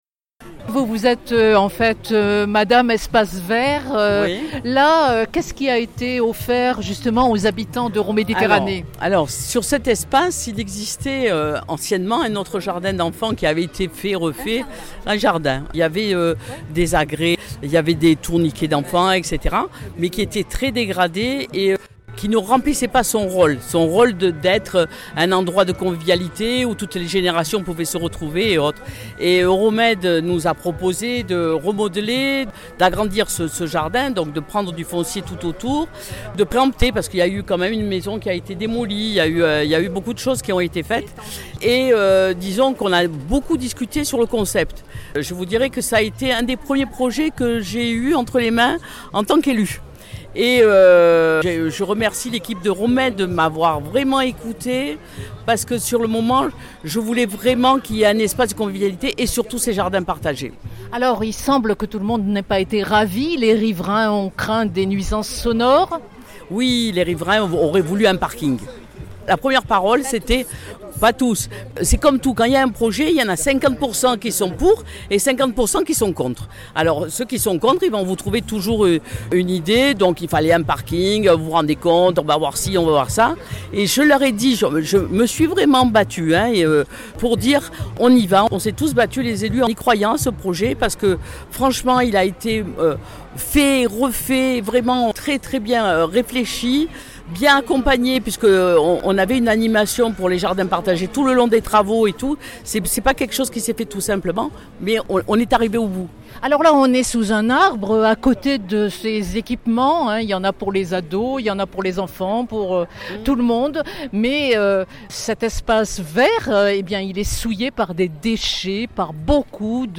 Au 14, rue de Ruffi dans le 3e arrondissement de Marseille, entre plants de fraises et barbe à papa, les habitants du quartier se pressaient pour cette fête de quartier organisée pour l’inauguration des Jardins de Ruffi à la fois jardin d’enfant et jardins partagés.